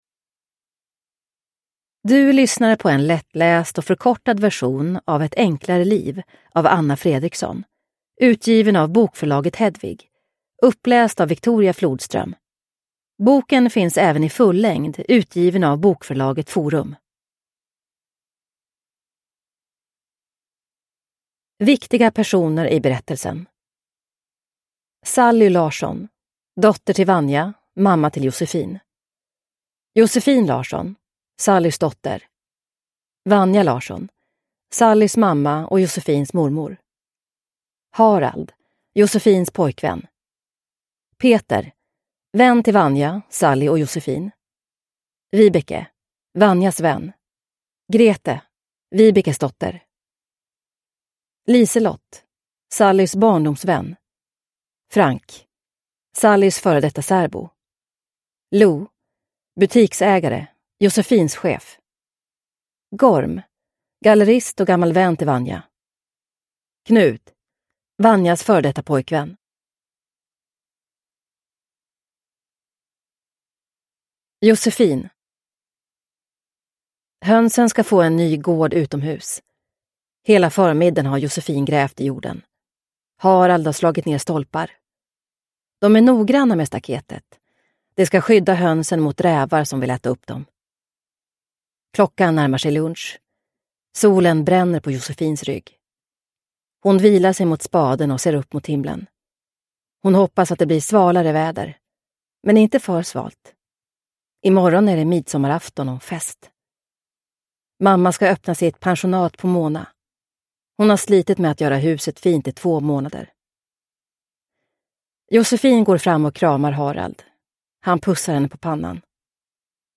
Ett enklare liv (lättläst) – Ljudbok